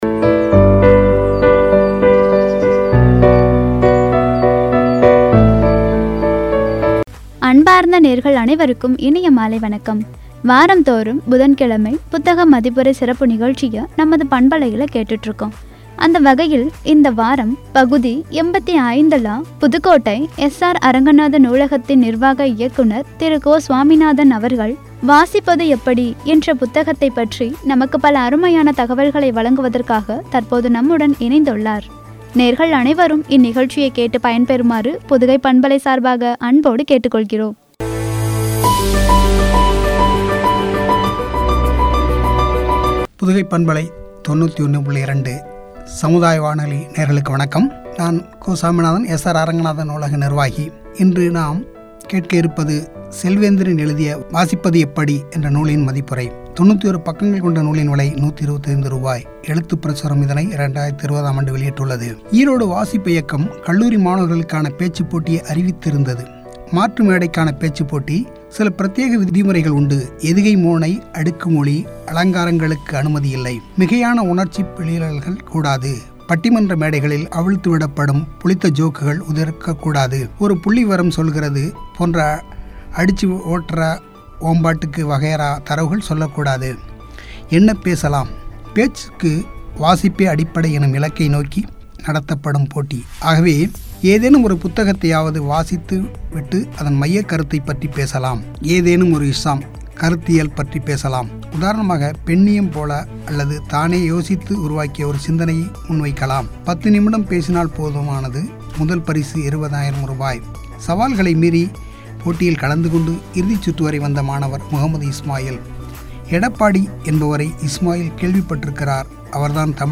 “புத்தக மதிப்புரை” (வாசிப்பது எப்படி- பகுதி 85) என்ற தலைப்பில் வழங்கிய உரை.